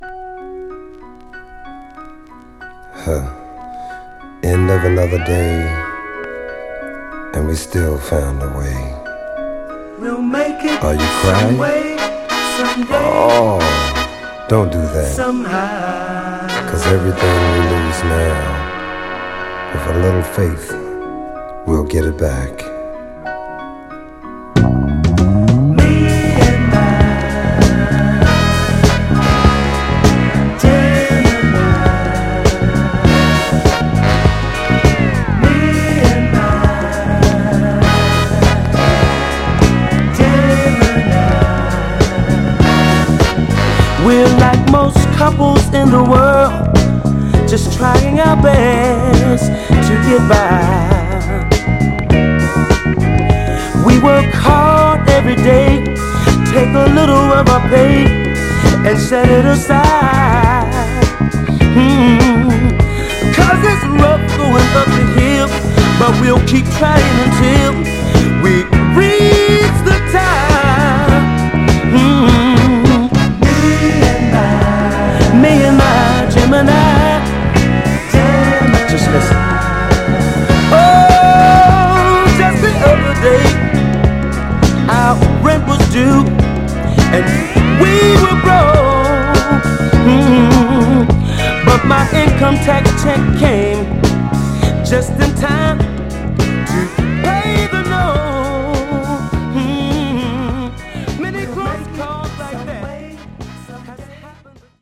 ボルチモア産のソウル・カルテット
フリップは(カラオケ用)インスト。
※試聴音源は実際にお送りする商品から録音したものです※